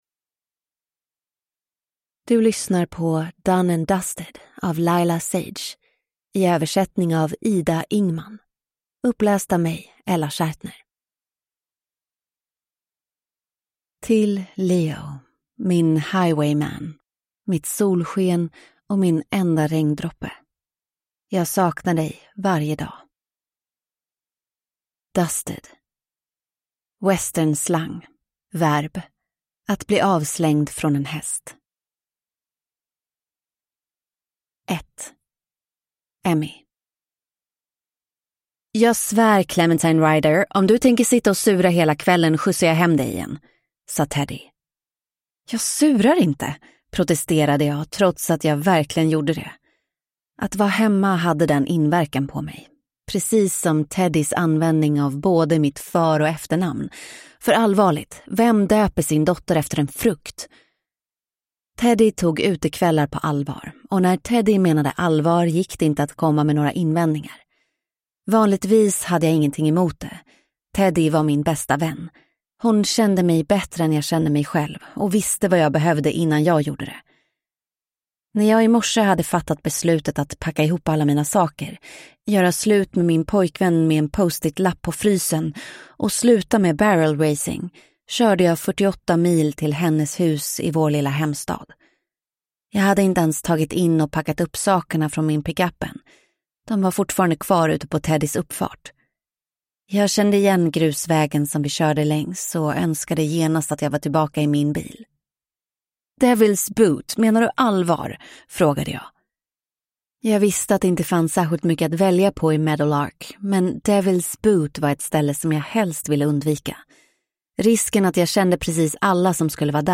Done and Dusted (svensk utgåva) (ljudbok) av Lyla Sage